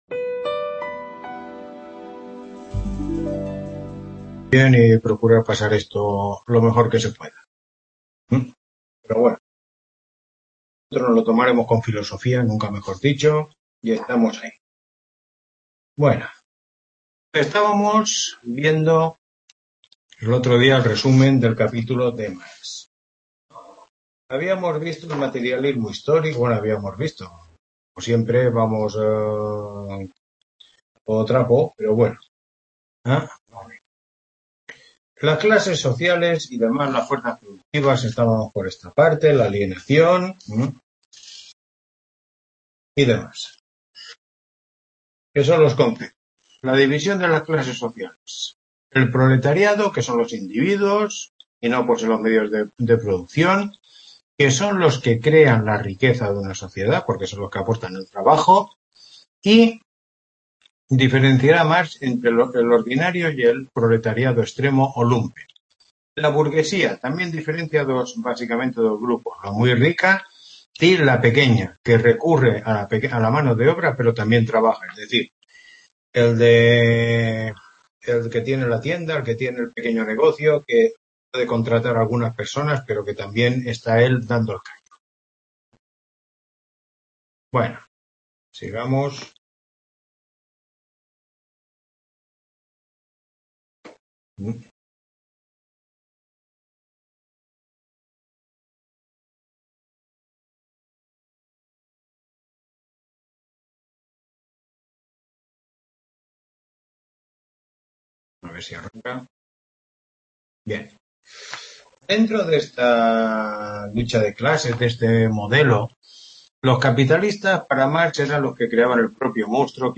Tutoría 6